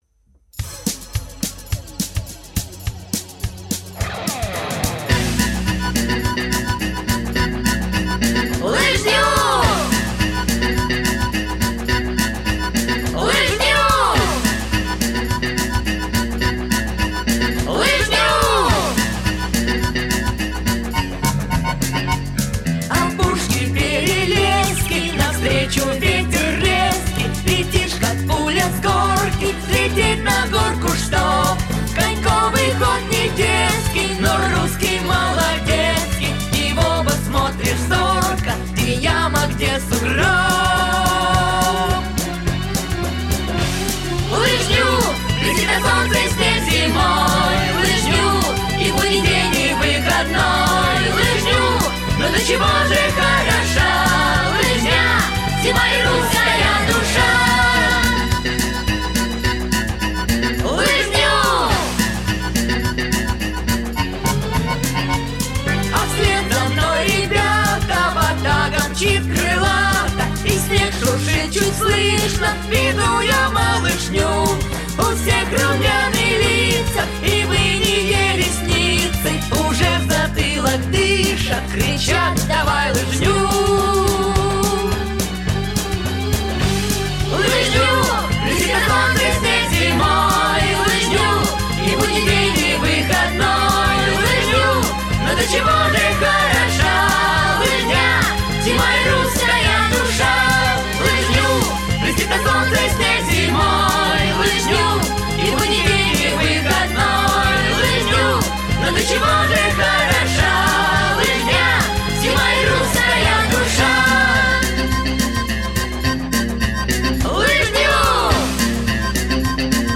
Аудиокнига Малышам о спорте | Библиотека аудиокниг